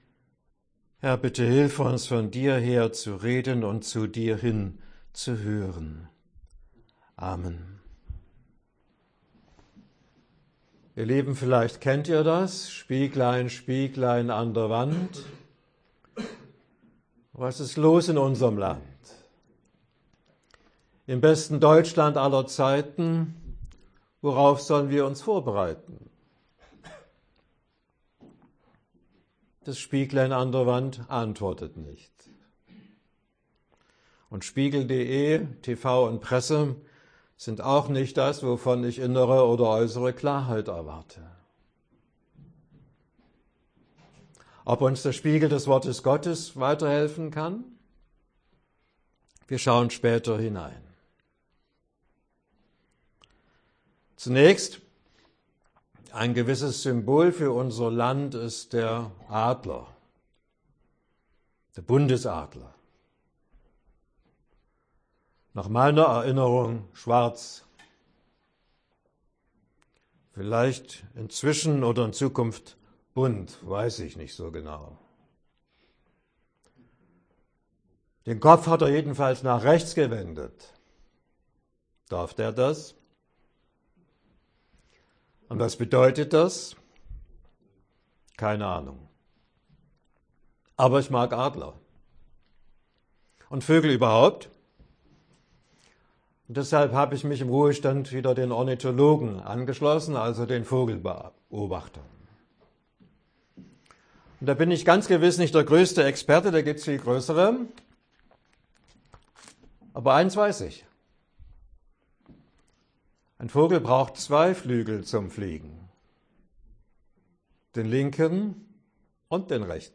Predigt für den Sonntag Septuagesimä im Februar 2025(vor den Wahlen)